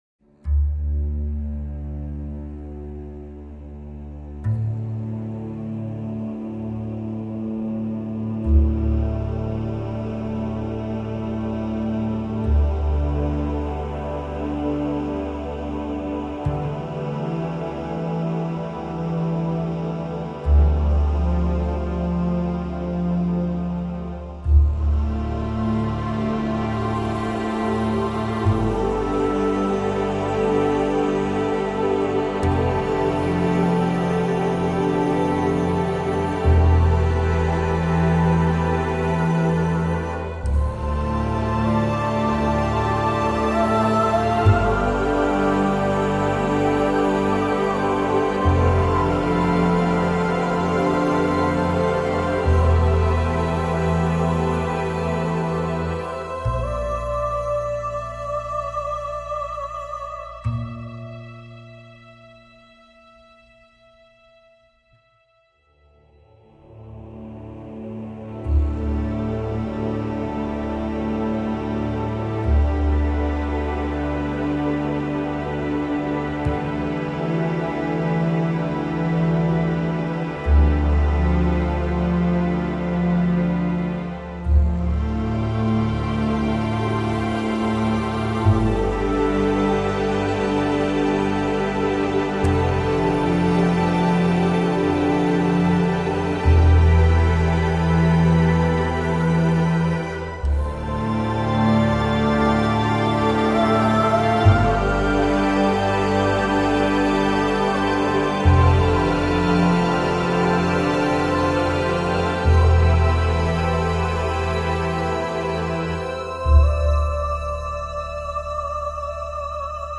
strings and chorus